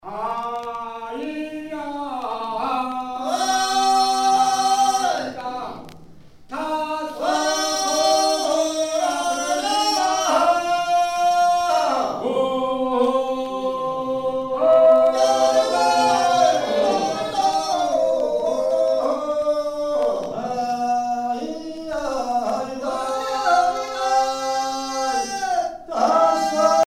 Chants de travail
Pièce musicale éditée